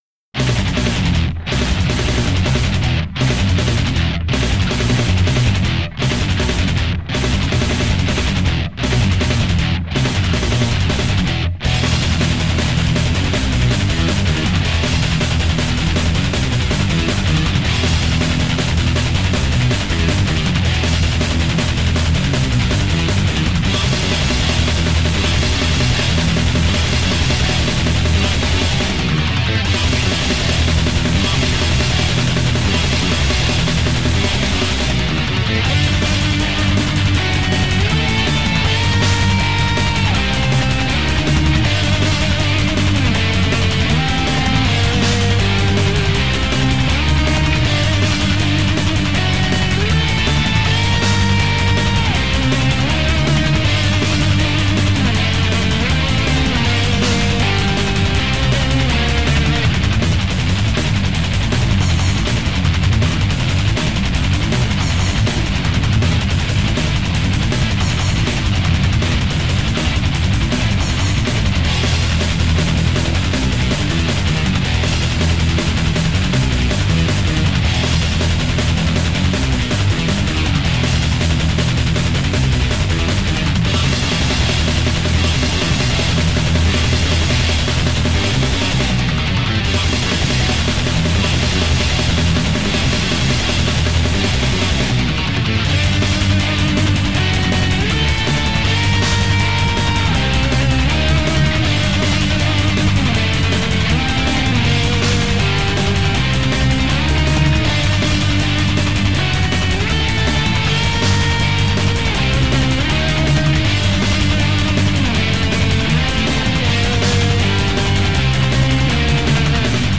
Progressive Instrumental, guitar-led metal at its finest!